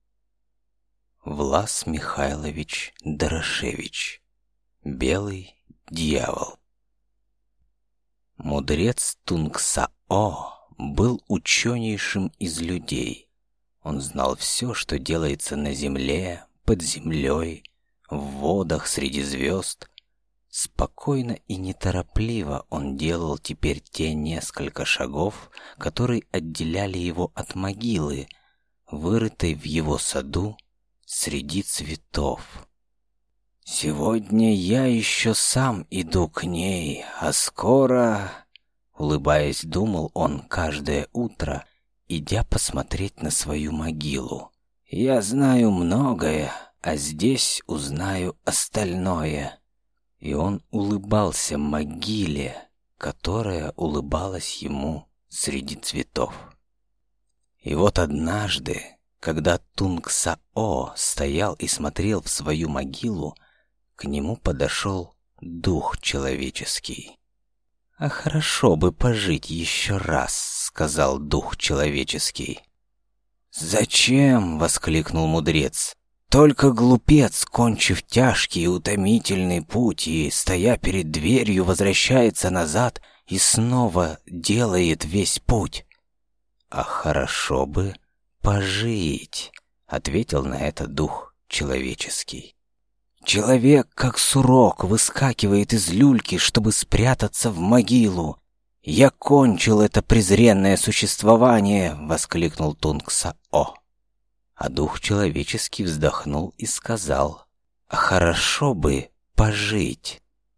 Аудиокнига Белый дьявол | Библиотека аудиокниг
Прослушать и бесплатно скачать фрагмент аудиокниги